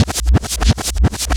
VEC3 Reverse FX
VEC3 FX Reverse 06.wav